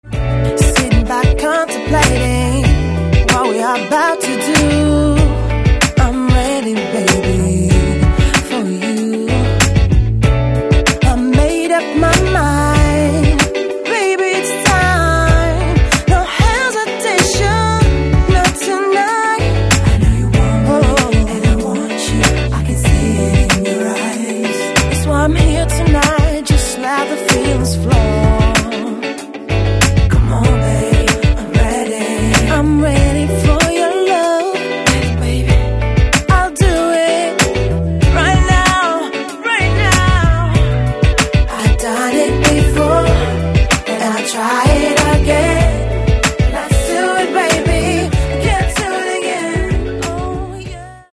Каталог -> Поп (Легкая) -> Подвижная